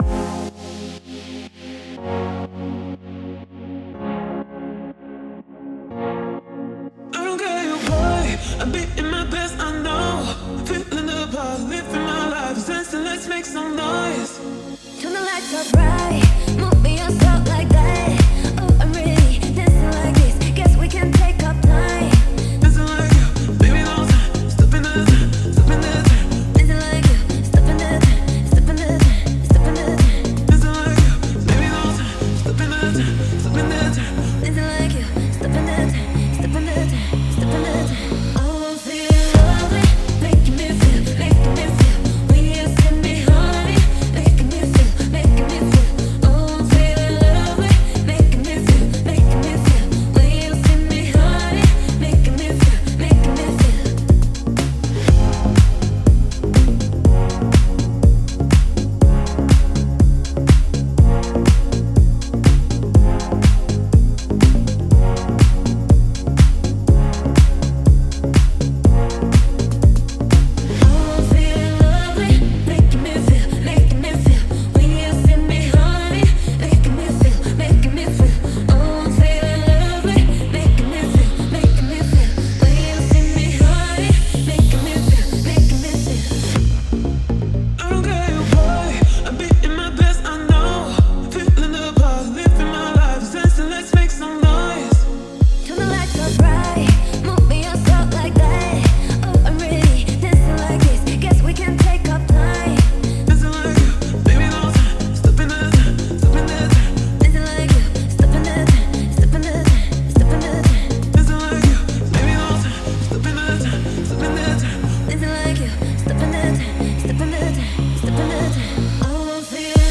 Another electric vibe